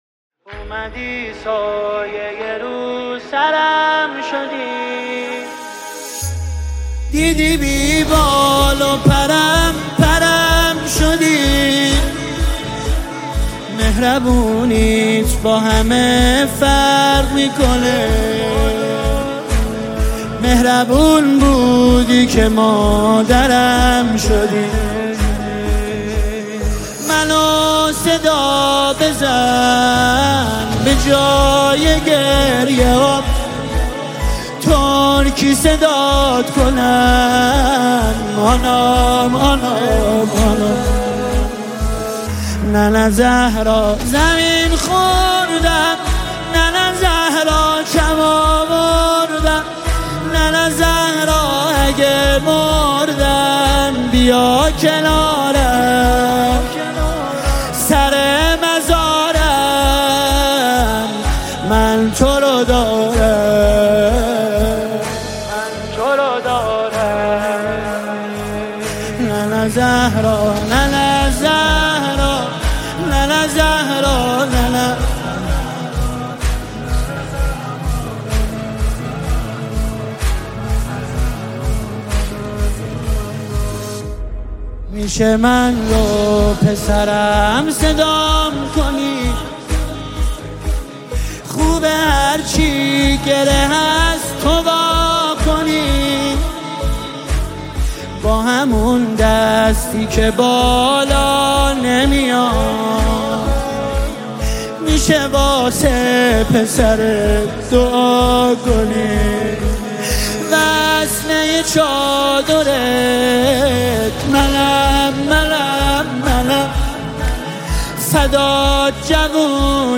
• نوحه و مداحی